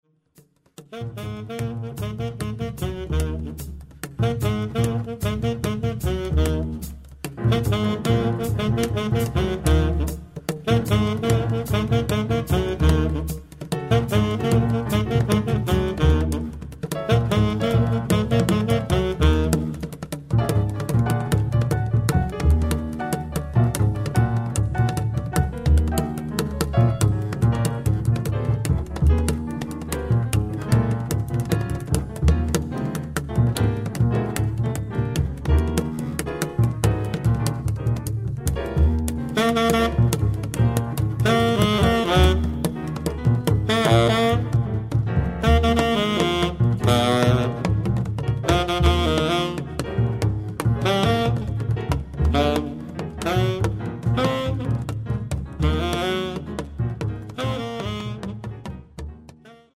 sax contralto, tenore, flauto, clarinetti
pianoforte
violoncello, contrabbasso
batteria, percussioni